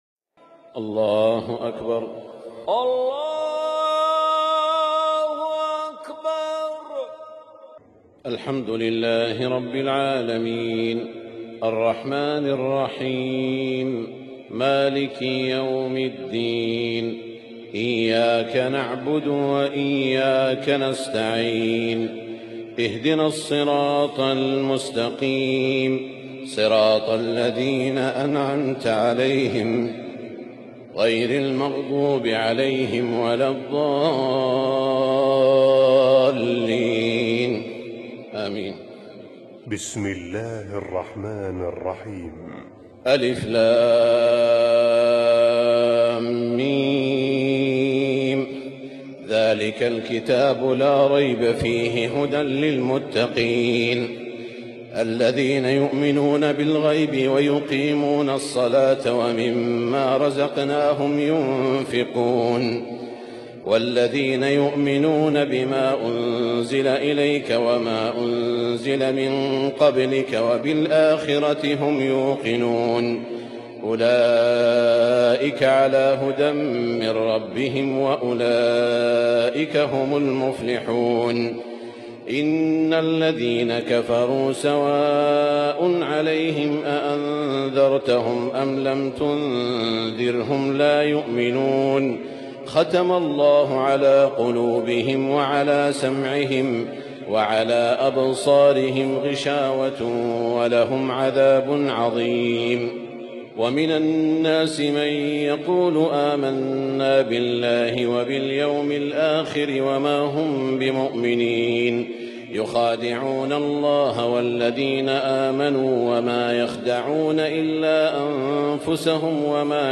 تراويح ليلة 1 رمضان 1441هـ من سورة البقرة (1-66) Taraweeh 1 st night Ramadan 1441H > تراويح الحرم المكي عام 1441 🕋 > التراويح - تلاوات الحرمين